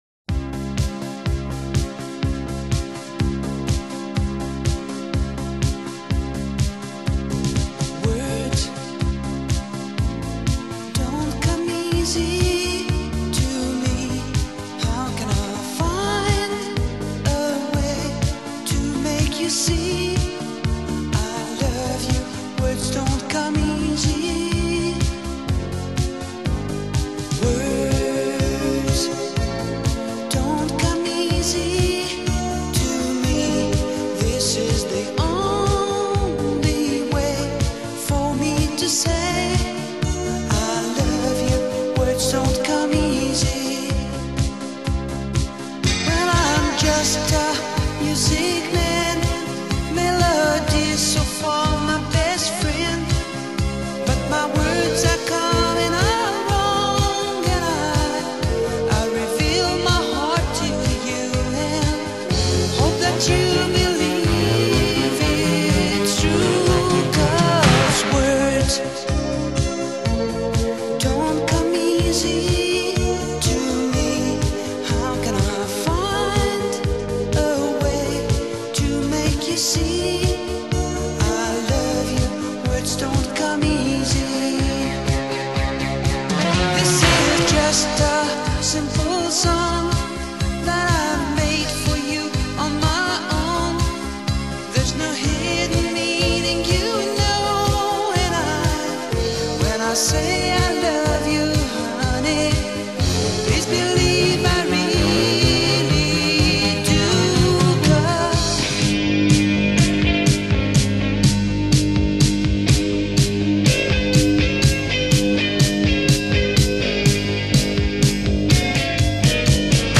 Synth-pop, Disco